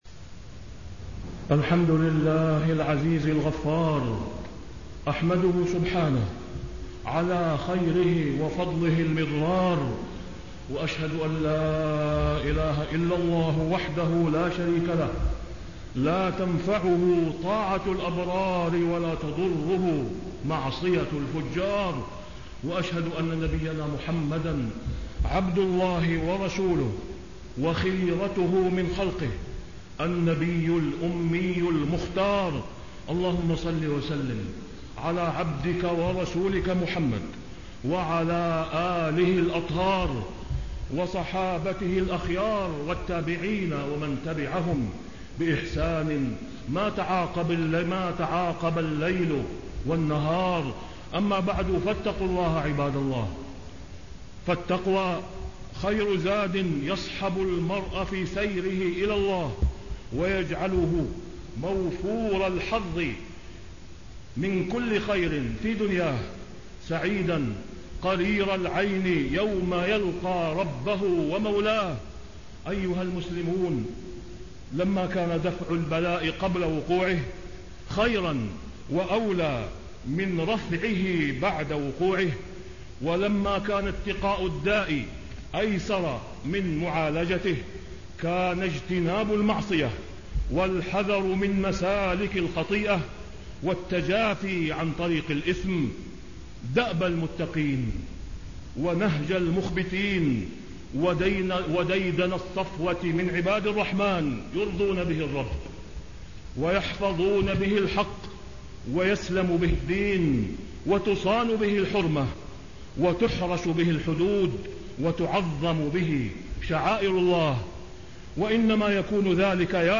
تاريخ النشر ٢٦ محرم ١٤٣٥ هـ المكان: المسجد الحرام الشيخ: فضيلة الشيخ د. أسامة بن عبدالله خياط فضيلة الشيخ د. أسامة بن عبدالله خياط النفس الأمارة وعقبات الشيطان